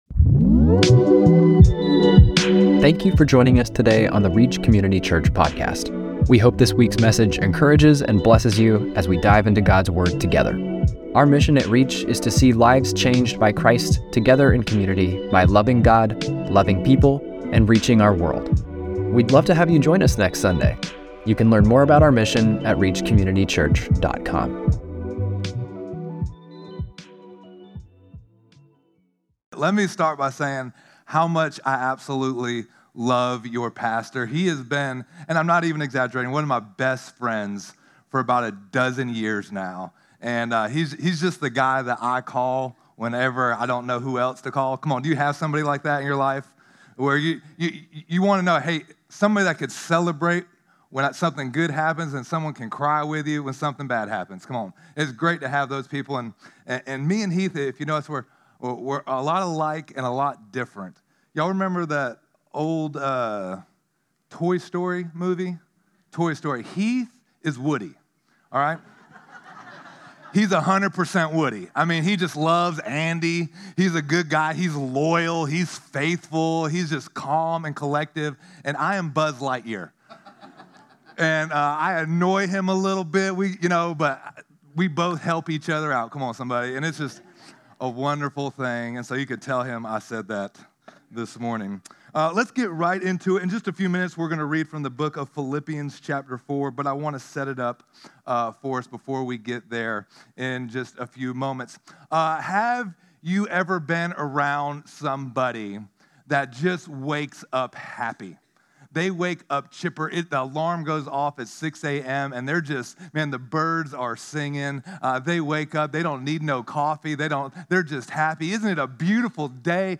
6-20-25-Sermon.mp3